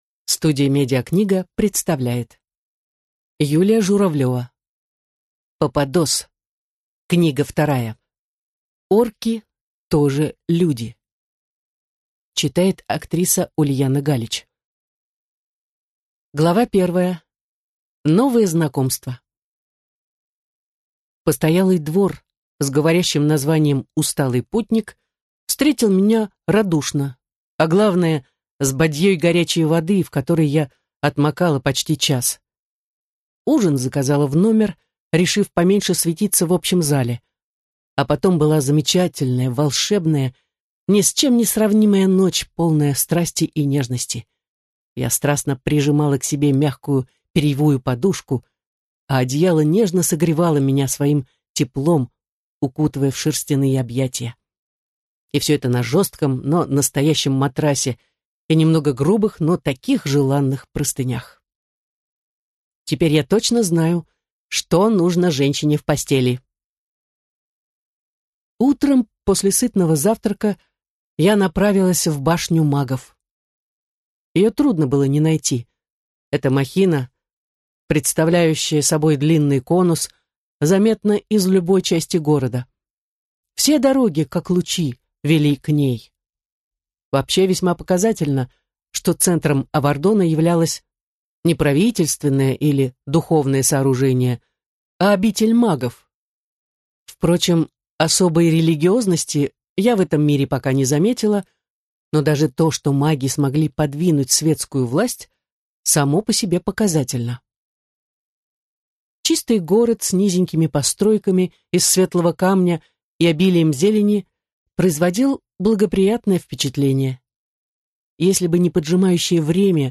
Аудиокнига Попадос 2. Орки тоже люди | Библиотека аудиокниг